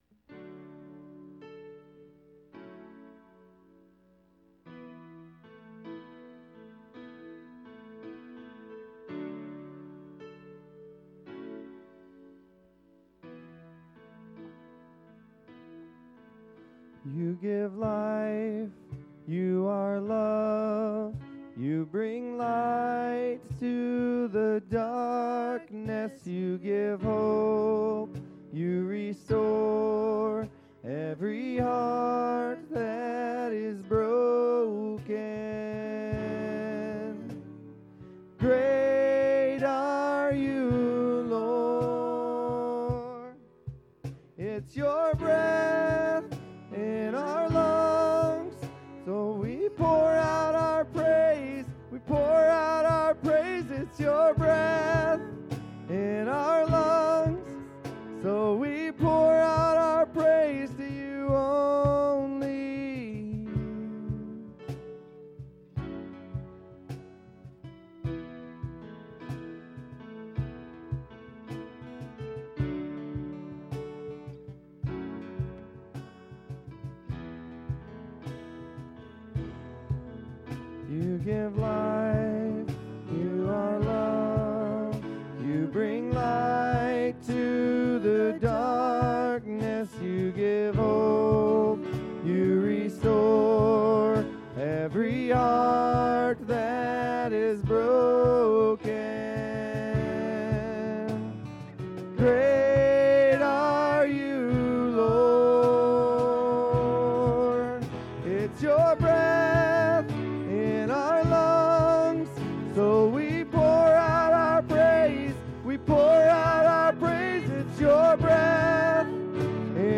Easter-Contemporary.mp3